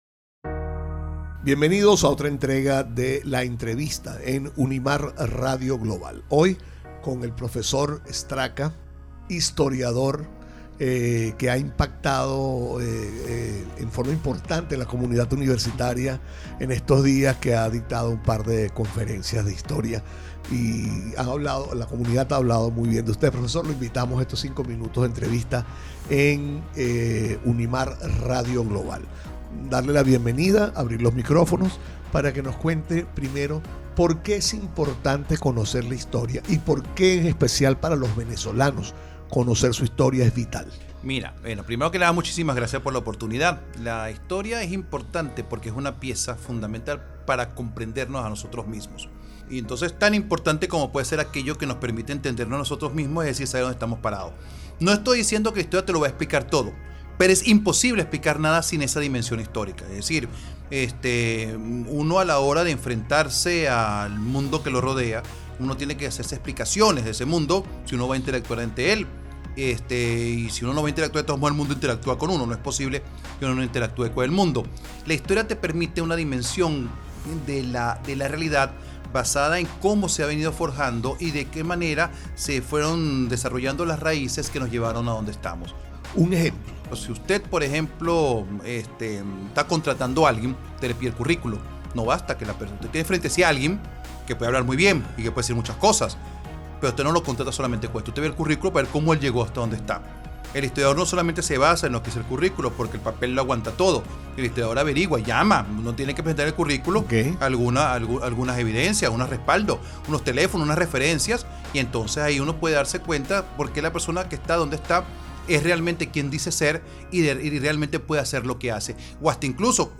Entrevistas
En cada episodio, invitaremos a profesores, investigadores, y figuras influyentes de la universidad para compartir sus conocimientos, proyectos y reflexiones.